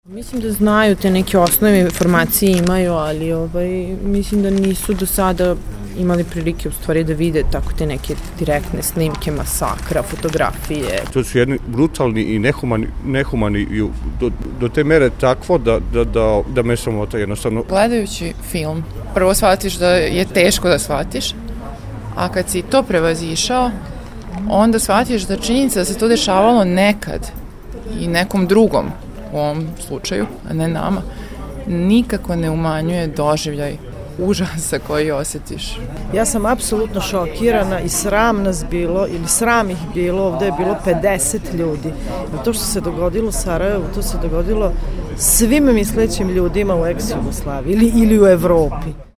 Nakon projekcije filma pitali smo Novosađane, šta misle koliko zapravo građani znaju o ovom zločinu: